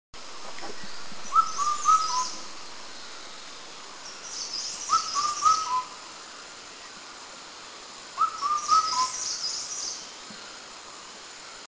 Cuculus micropterus
Indian Cuckoo
IndianCuckoo.mp3